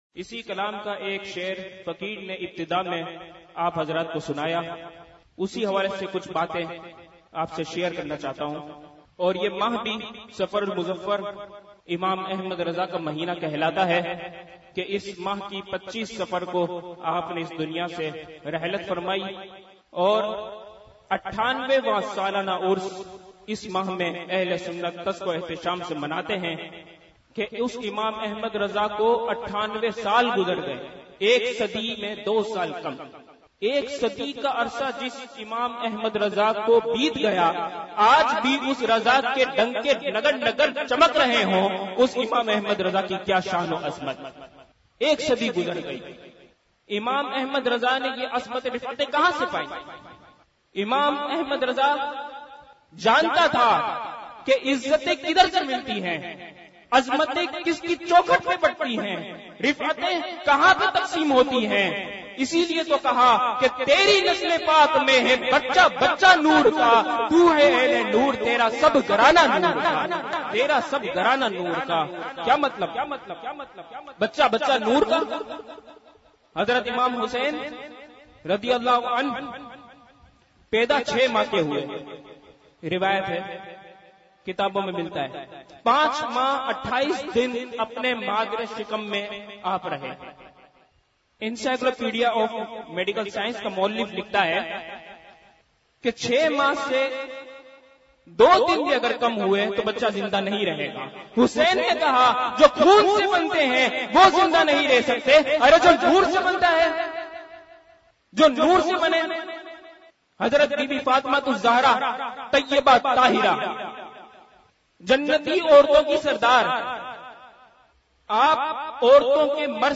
بیانات